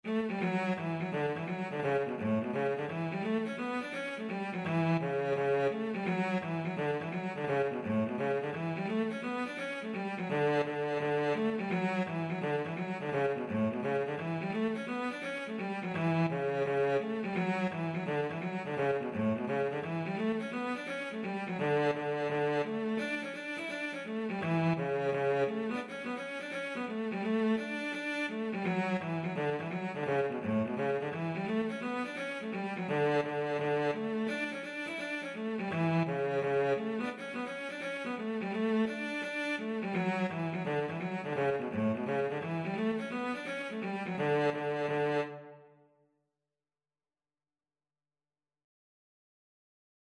D major (Sounding Pitch) (View more D major Music for Cello )
4/4 (View more 4/4 Music)
Cello  (View more Easy Cello Music)
Traditional (View more Traditional Cello Music)